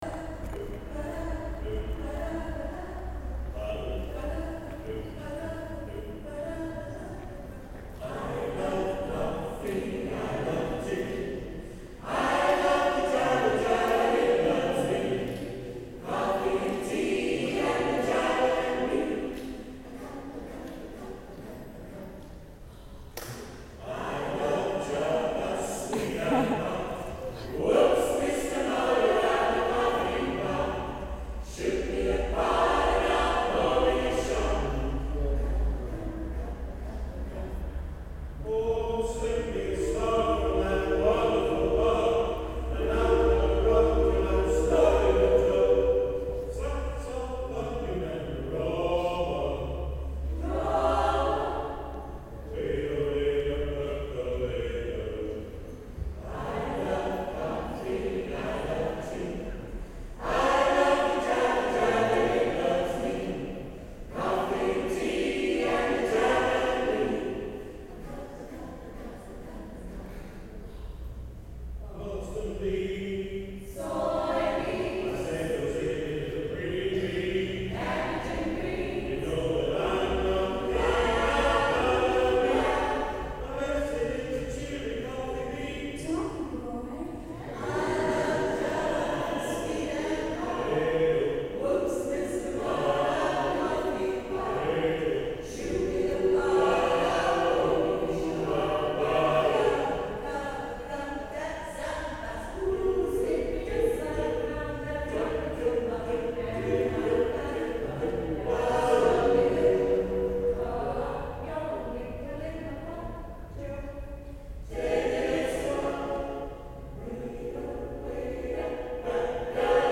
There was some fun sign singing from Deaf group the Alexandras and Open Arts Community Choir from Northern Ireland, as seen on Last Choir Standing, regaled us with some fabulous choral singing.
Here's a clip I recorded later at the night time show.
Open Arts Choir (MP3)
open_arts_choir.MP3